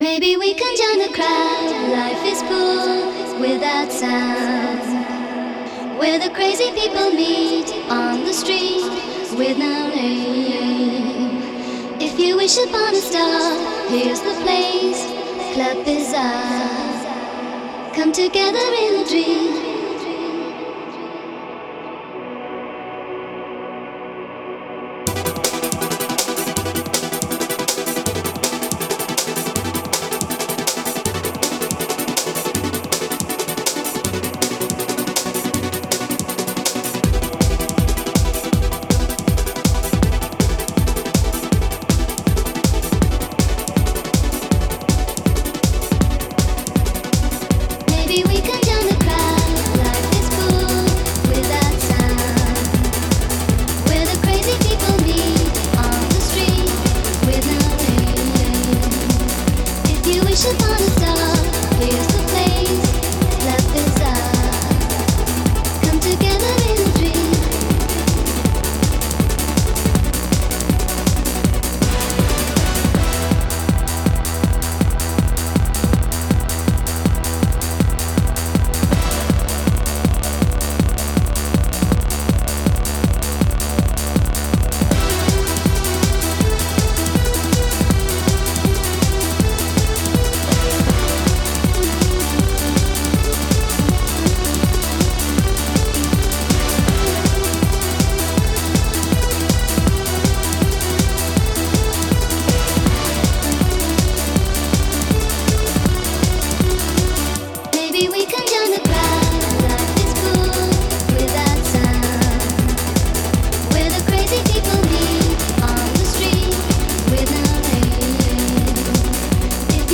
Genre: Hard Trance.